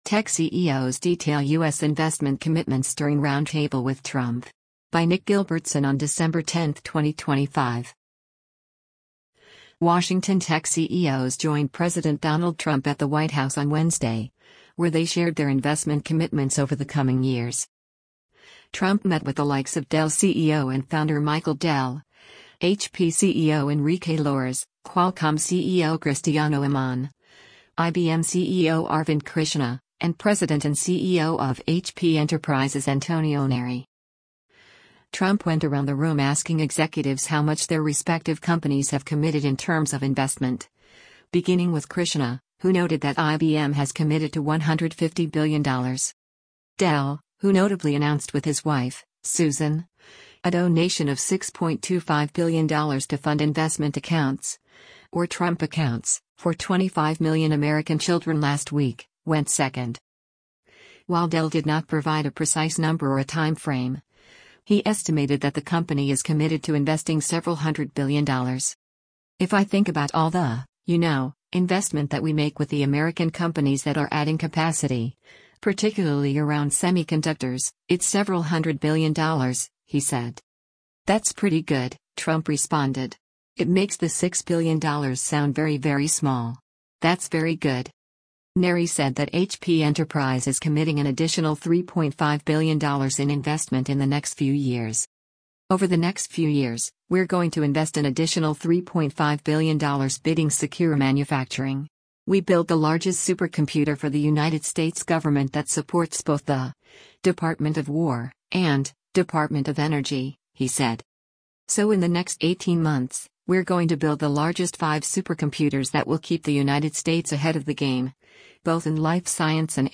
Tech CEOs Detail U.S. Investment Commitments at Roundtable with Trump
CEO of Dell Technologies Michael Dell (L) speaks during a roundtable discussion with U.S.
WASHINGTON–Tech CEOs joined President Donald Trump at the White House on Wednesday, where they shared their investment commitments over the coming years.